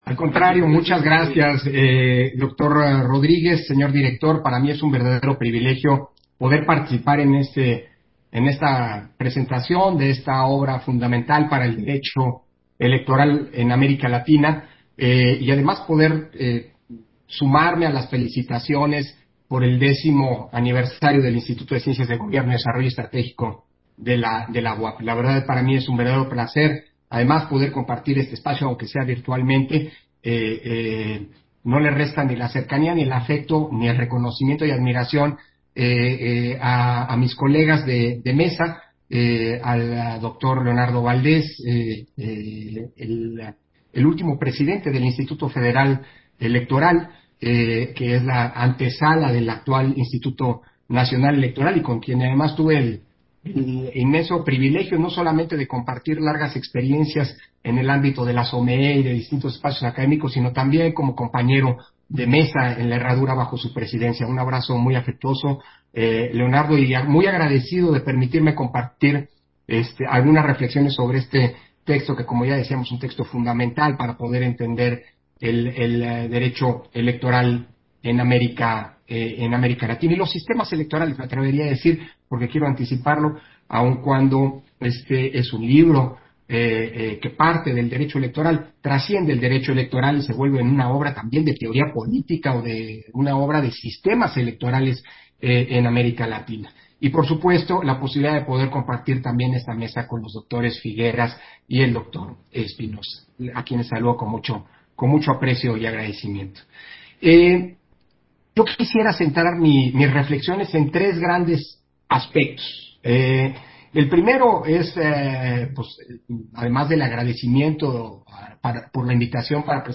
Participación de Lorenzo Córdova, durante la presentación del libro, Derecho Electoral Latinoamericano